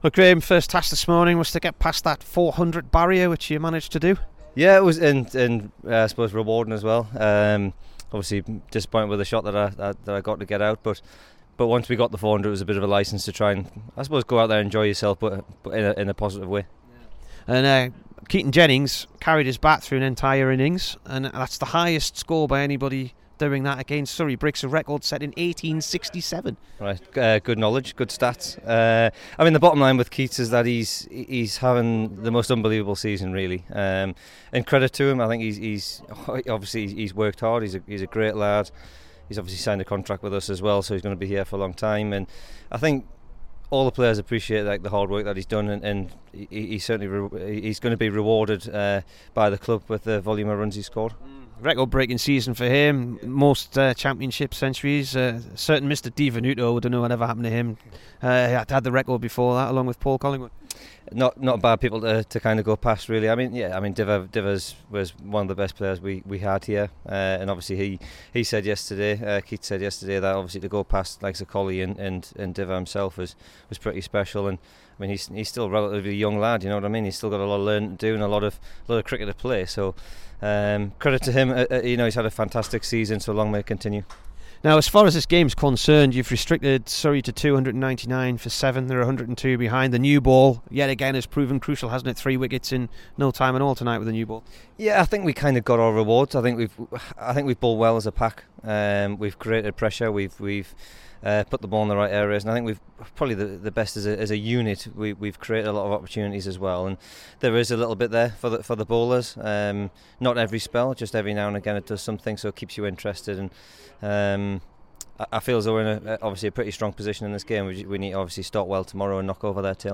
HERE'S THE DURHAM BOWLER AFTER HE TOOK FOUR WICKETS ON DAY TWO V SURREY.